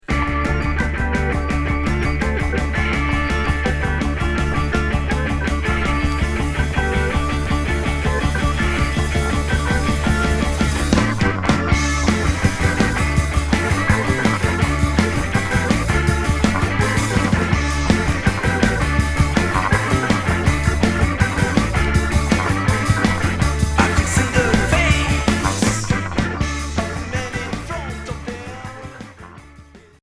Garage psyché